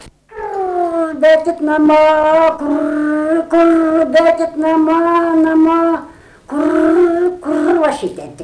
smulkieji žanrai
vokalinis